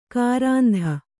♪ kārāndha